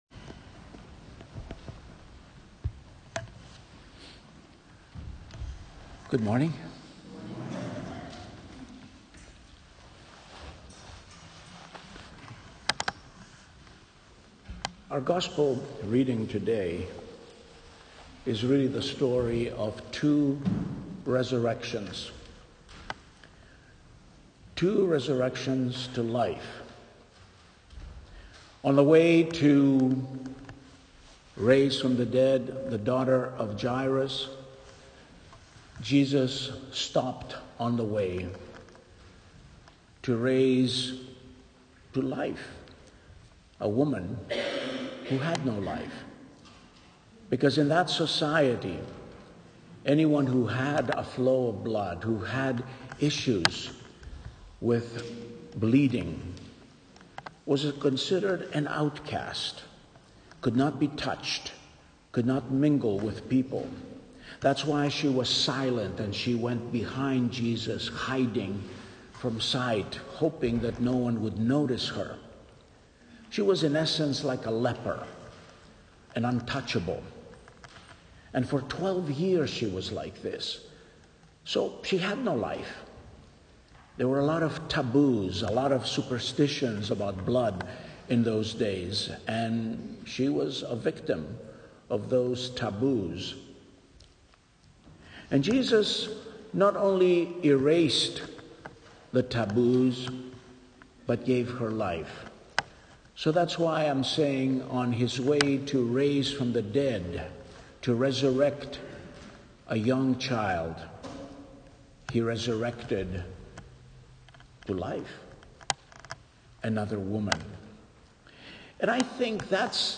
It’s about the miracles that take place on the way to resurrection! [Hear the rest of the sermon here:]